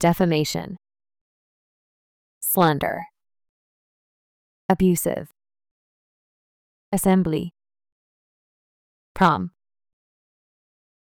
音声を再生し、強勢のある母音（＝大きな赤文字）を意識しながら次の手順で練習しましょう。